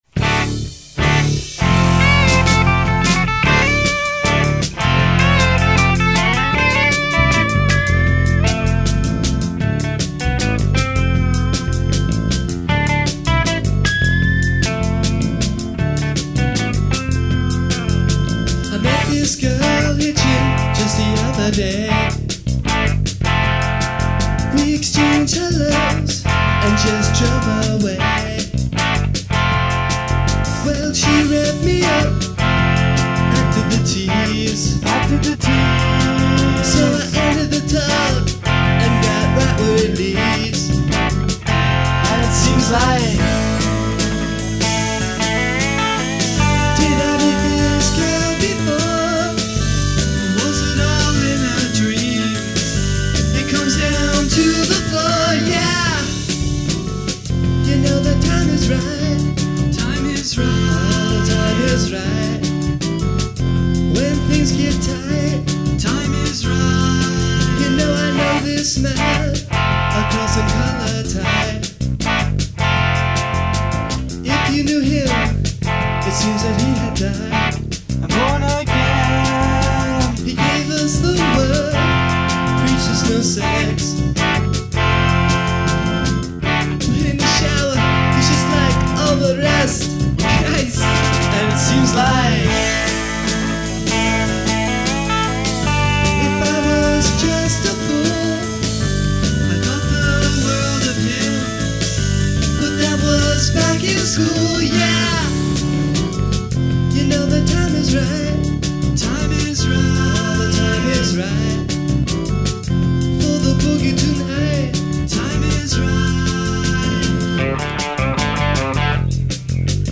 During that weekend the group recorded eleven songs on the Tascam 22-4, 4-track, reel-to-reel, tape recorder.